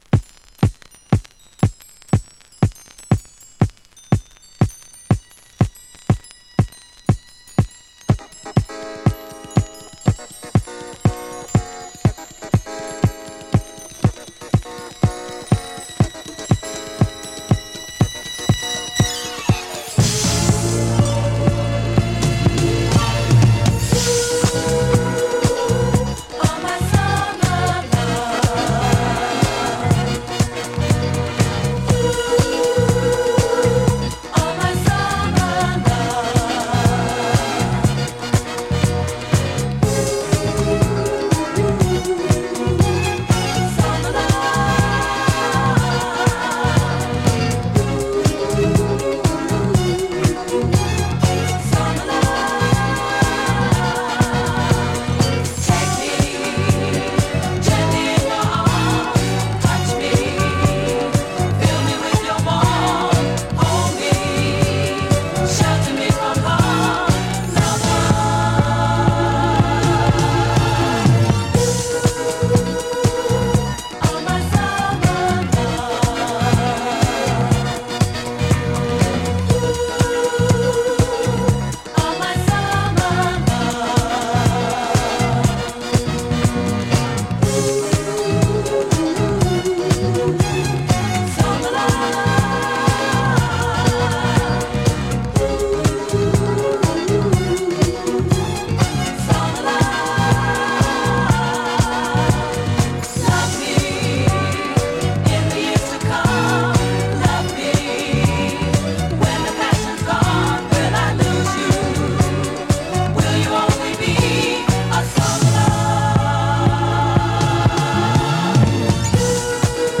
[DISCO] [BOOGIE] cat number
uplifting disco number
There is a very small amount of dust noise.